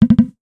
NOTIFICATION_Pop_05_mono.wav